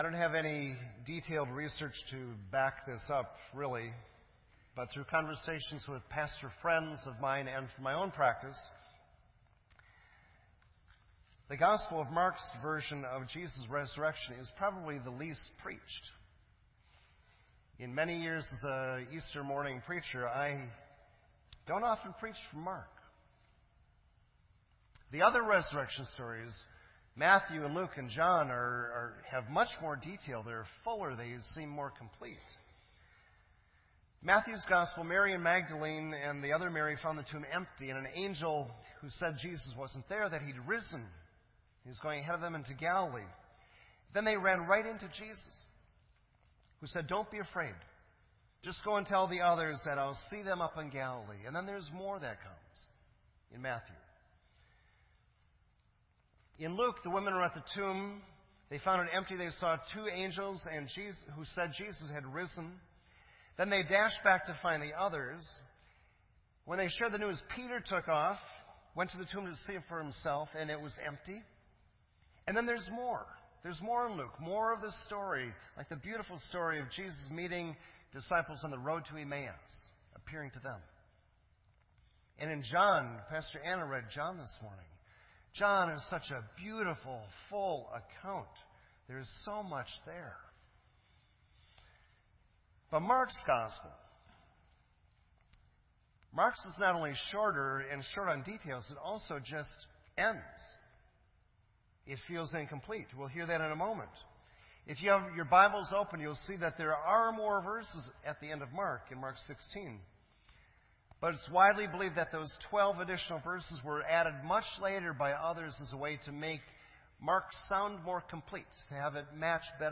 This entry was posted in Sermon Audio on April 2